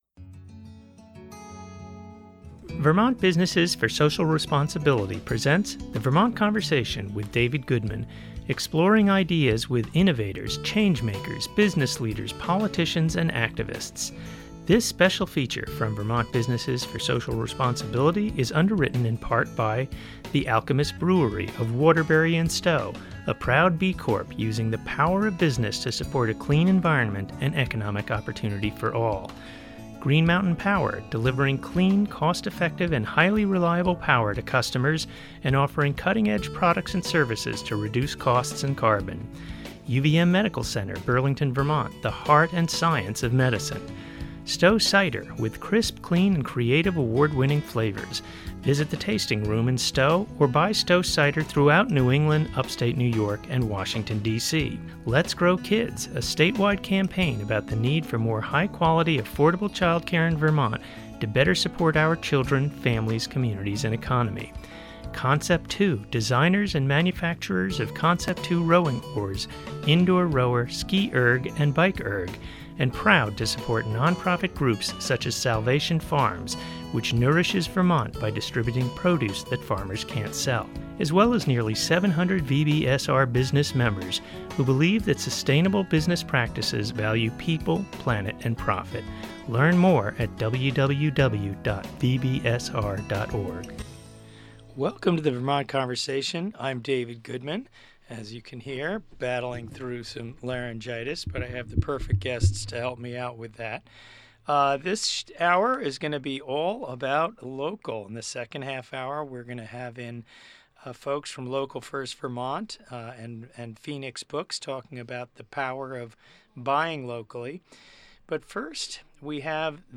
This Vermont Conversation was recorded live at Bridgeside Books in Waterbury, VT.